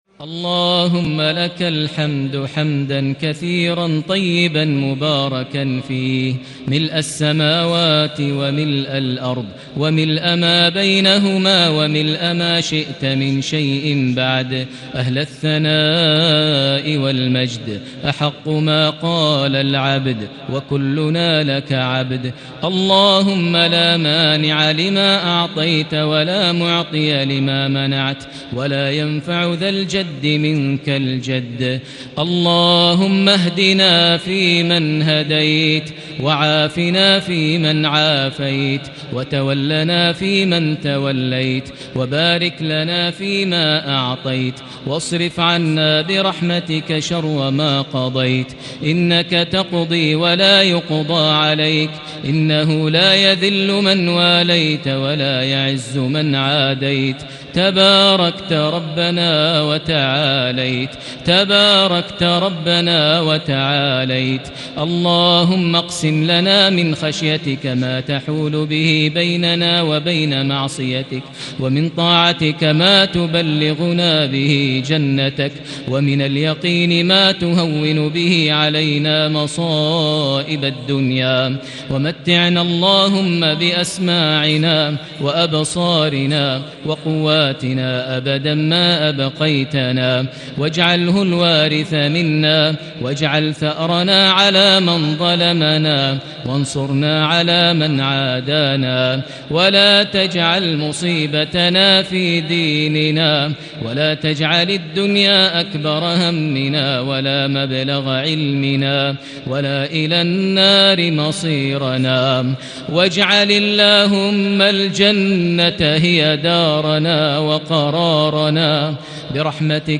دعاء القنوت ليلة 15 رمضان 1440هـ | Dua for the night of 15 Ramadan 1440H > تراويح الحرم المكي عام 1440 🕋 > التراويح - تلاوات الحرمين